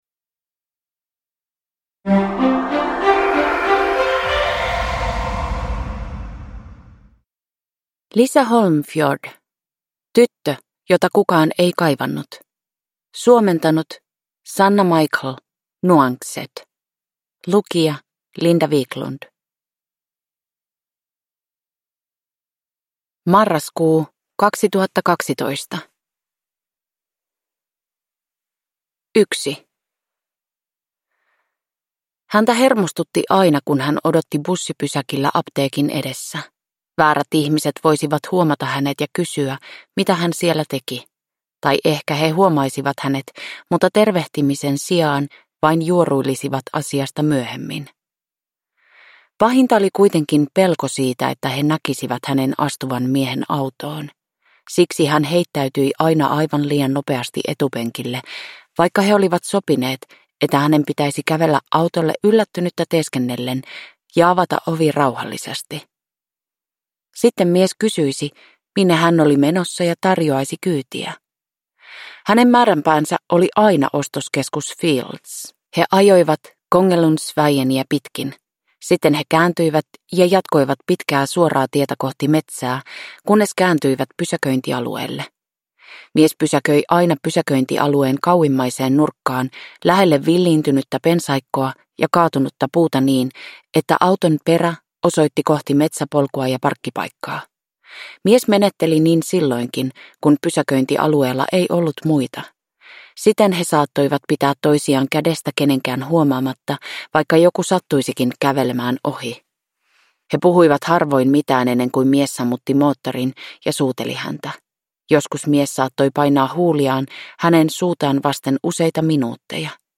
Tyttö jota kukaan ei kaivannut (ljudbok) av Lisa Holmfjord